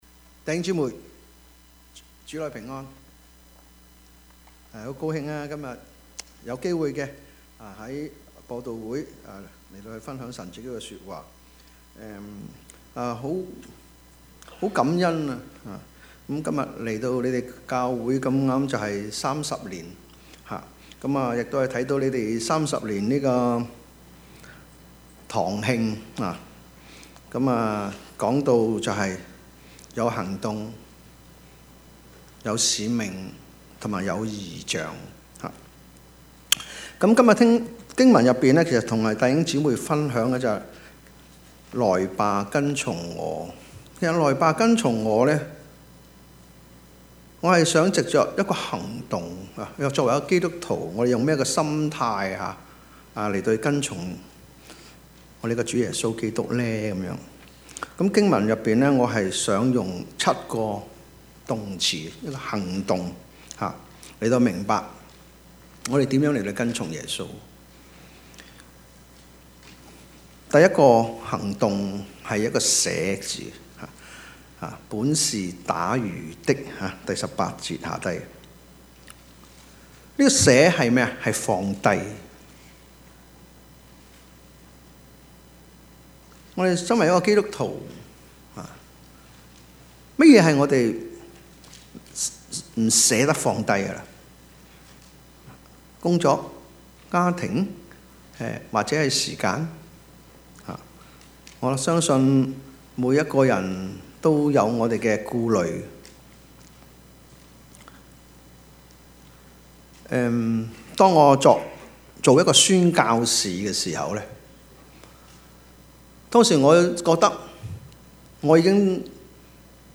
Service Type: 主日崇拜
Topics: 主日證道 « 真割禮 沒有保留的順服 »